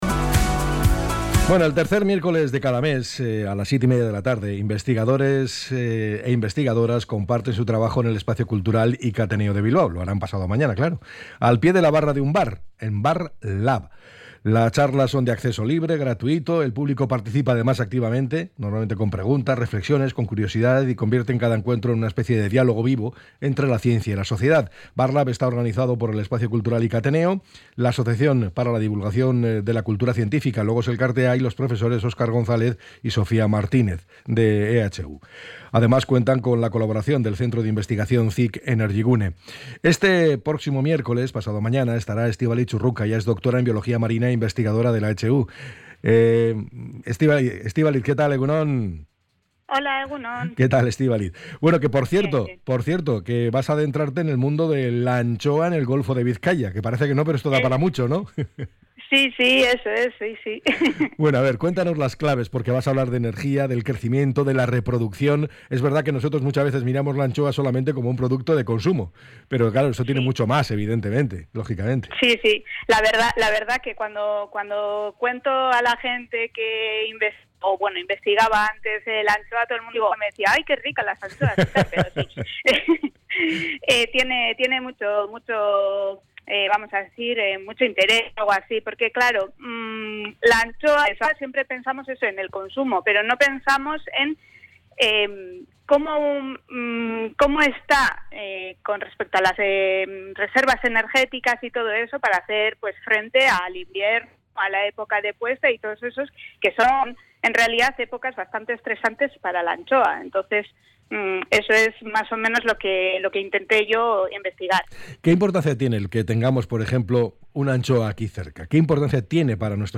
La entrevistadora también ha puesto el foco en la salud de nuestros mares.